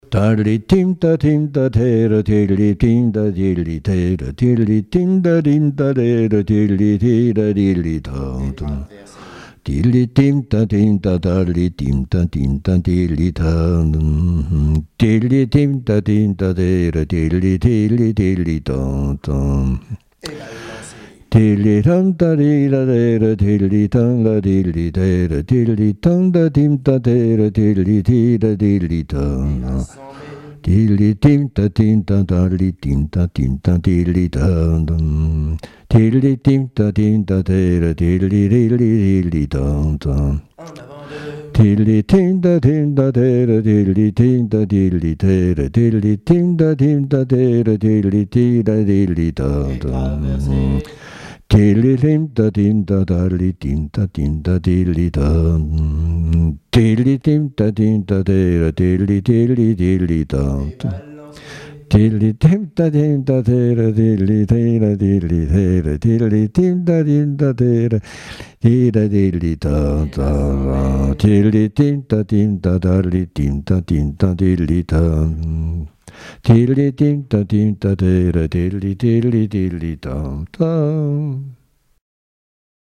Saint-Prouant
danse : quadrille : avant-deux
Témoignages et chansons
Pièce musicale inédite